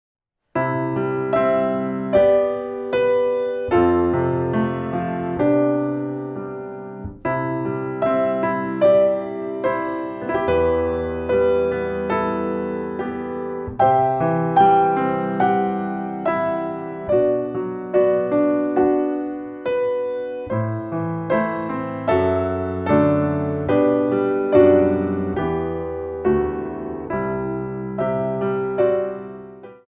2 bar intro 4/4
32 bars